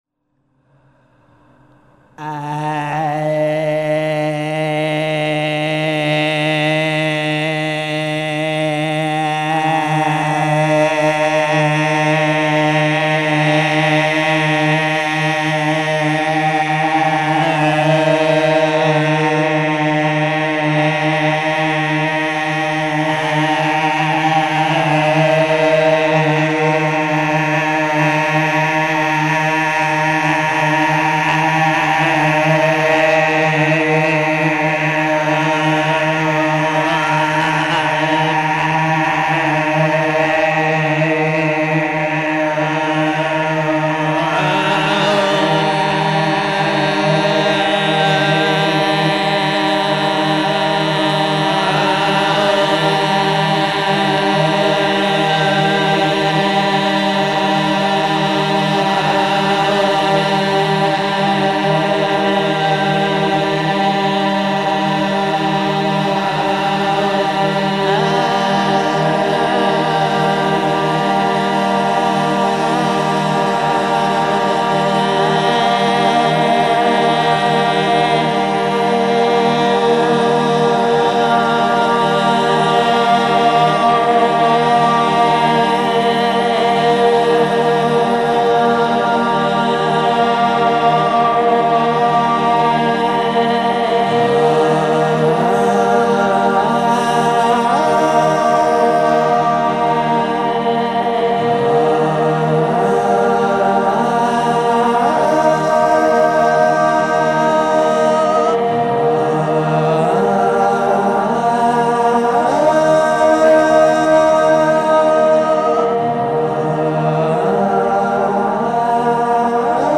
In one of the small production studios they had two Studer 1/4″ 2 track open reel tape machines and, in a fit of creativity spurred by boredom, I hooked them into a ‘Frippertronics’ set-up.
The playback of the machine on the right would be fed back into the machine on the left, along with whatever new sound was added, and the whole thing would repeat, and slowly fade out. The speed of the repeats was a function of the tape speed and the distance between the two machines.
Here’s a lame graphic I created to illustrate the process: I was digging through some old files and found two that exemplify this process: Dreams Go Down EAO I added a bit of reverb after, just ’cause.